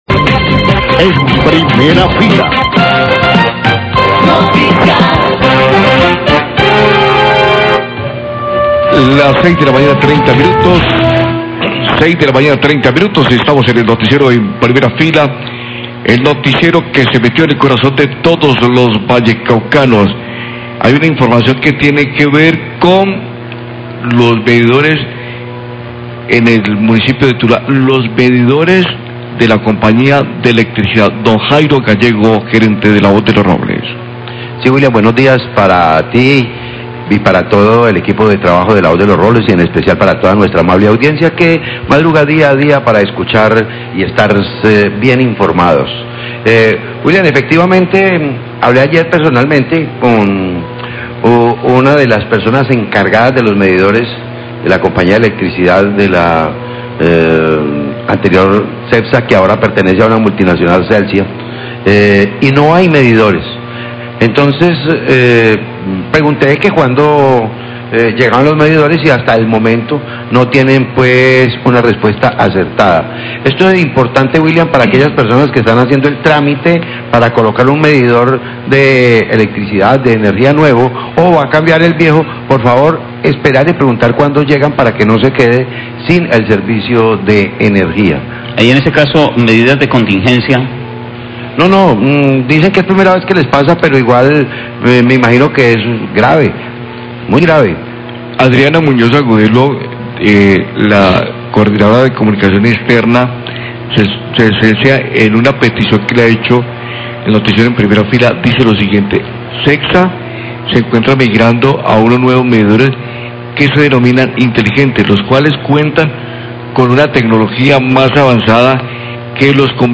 Radio
Hay denuncias de la comunidad porque en los almacenes de cetsa están agotados los medidores. cetsa informa que en tuluá se está migrando a nuevo tipo de medidores inteligentes y que a eso se deben los problemas en la distribución de los mismos. La comunidad se comunica con el noticiero reaccionando a la información para reportar inconformidades por el costo de los recibos de energía.